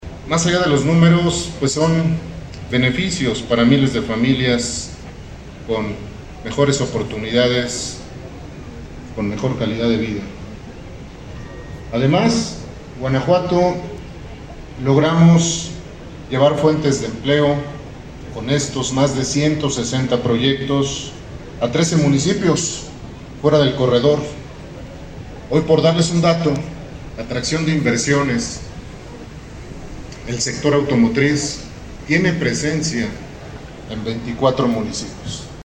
AudioBoletines
Ramón Alfaro, secretario de Desarrollo Económico